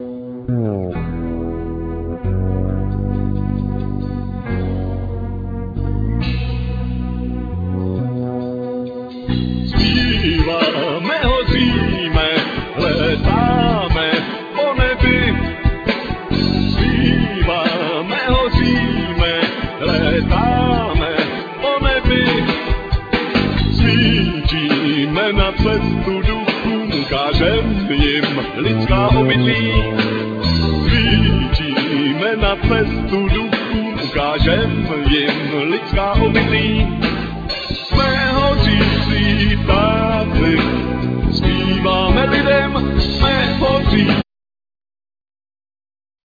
Vocals,Guitars,Drums programming,Saxophones
Drums
Bass,Opera Voice
Keyboards,Backing vocals
Sound effects,Noises